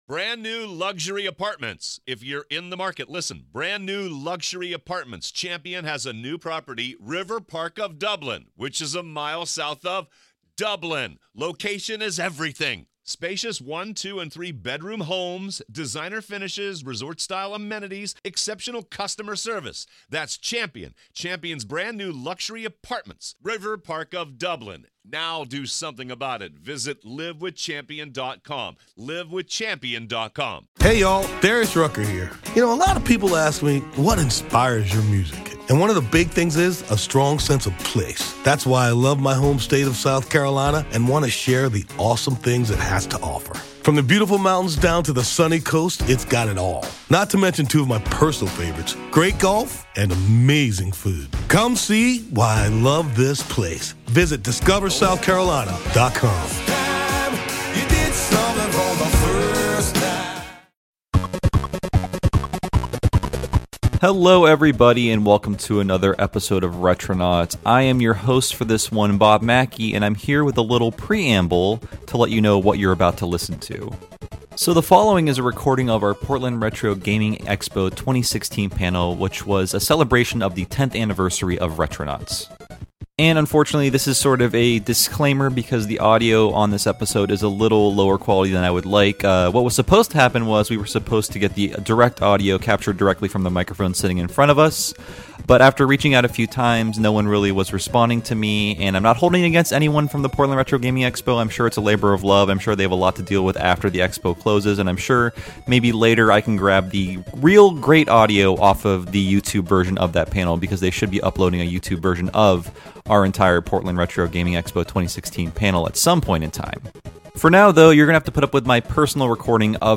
Retronauts Episode 78: Retronauts' 10th Anniversary Live from PRGE 2016